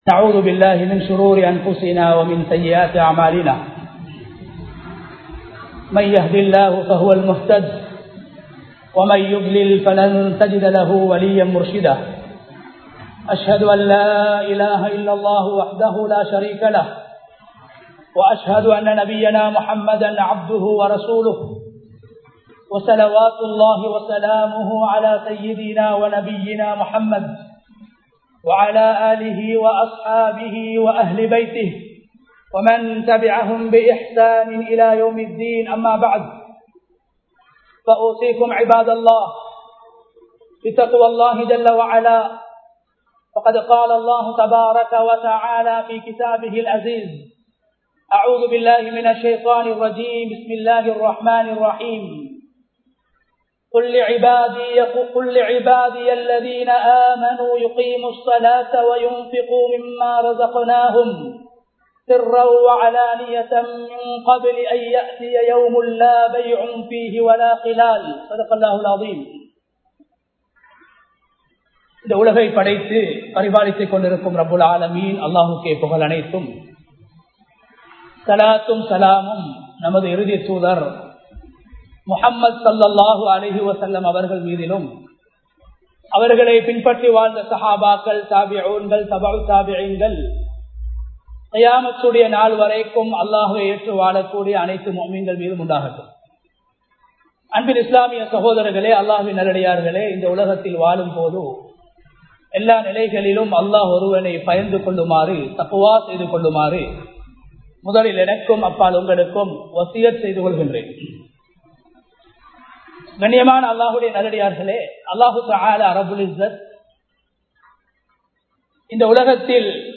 ரமழானும் ஏழைகளும் | Audio Bayans | All Ceylon Muslim Youth Community | Addalaichenai